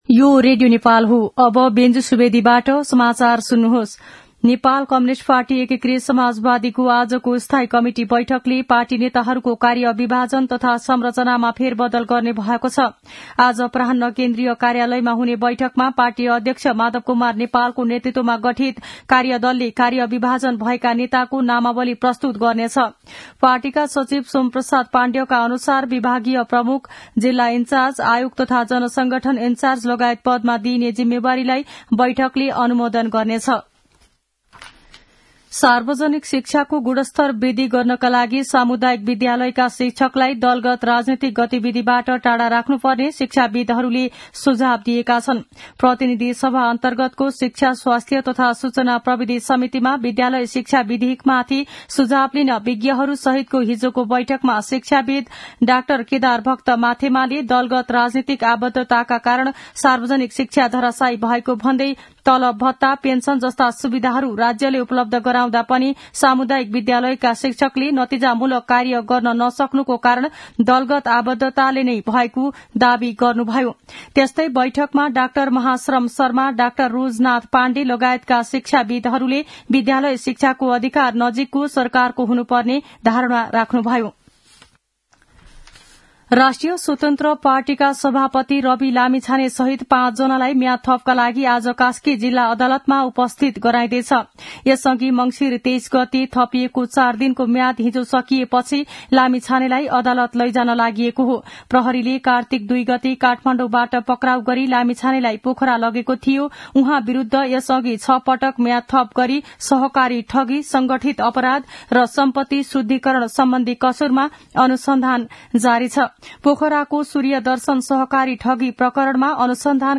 दिउँसो १ बजेको नेपाली समाचार : २८ मंसिर , २०८१
1-pm-nepali-news-1-10.mp3